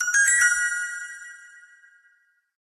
Ringtones Category: Message